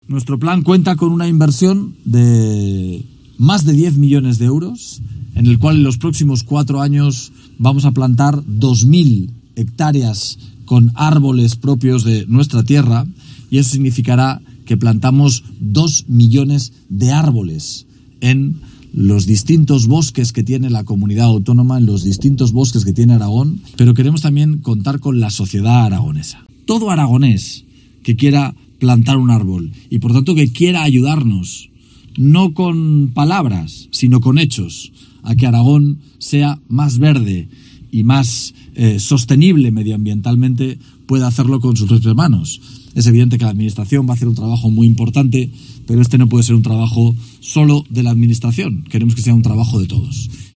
Declaraciones de Azcón esta mañana en su visita a la localidad de Perdiguera, donde acaban de comenzar los trabajos de reforestación de la zona, 5 años después de uno de los incendios forestales más graves de la provincia de Zaragoza, en el que ardieron 869 hectáreas.